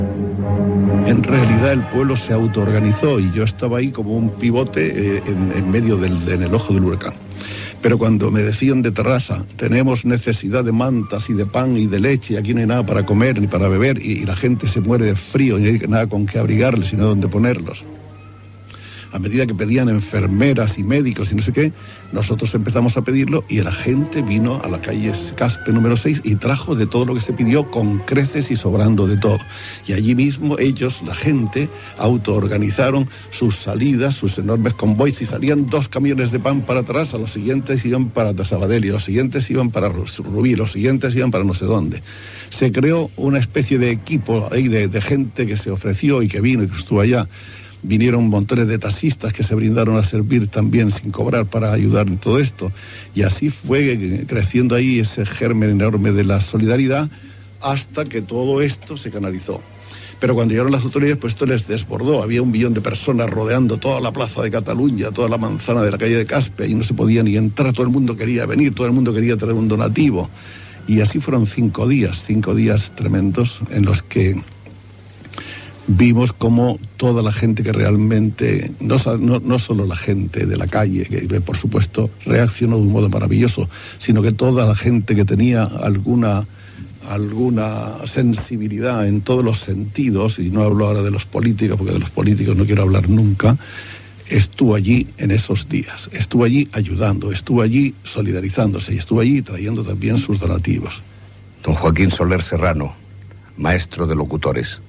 Joaquín Soler Serrano explica com va ser la campanya benèfica de Ràdio Barcelona en favor de les víctimes de les riuades del Vallès de 1962
Programa presentat per Joan Manuel Serrat.
Fragment extret del programa "La radio con botas", emès per Radio 5 l'any 1991